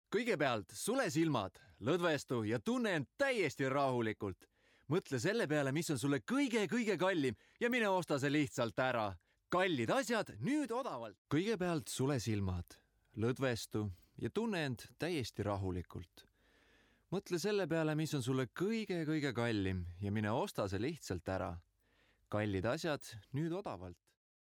Männlich